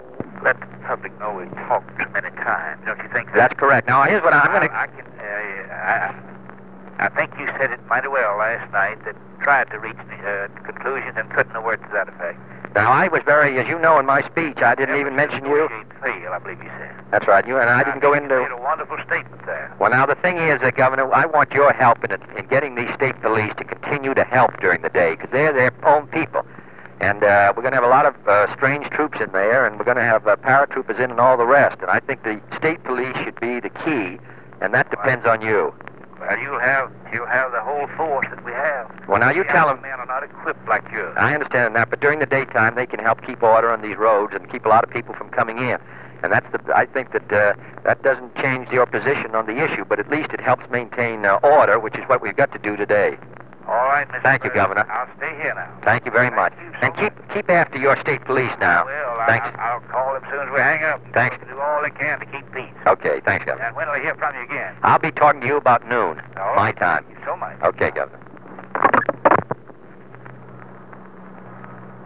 Conversation with Ross Barnett (8:46 am)
Secret White House Tapes | John F. Kennedy Presidency Conversation with Ross Barnett (8:46 am) Rewind 10 seconds Play/Pause Fast-forward 10 seconds 0:00 Download audio Previous Meetings: Tape 121/A57.